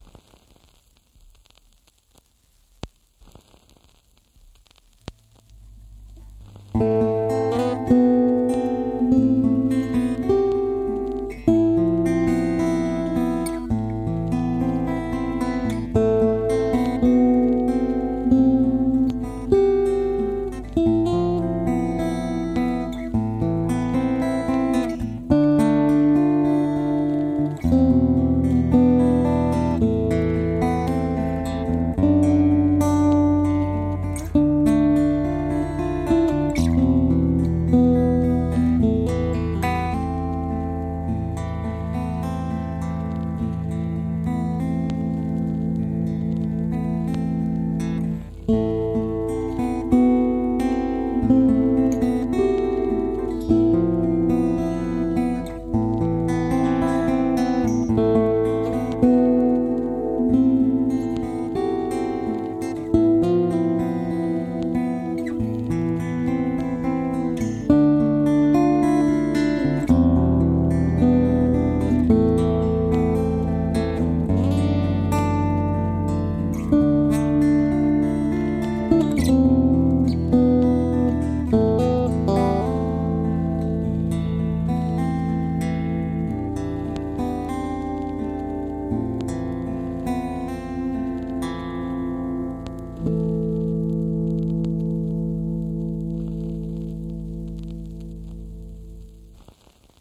/ Classical / Orchestral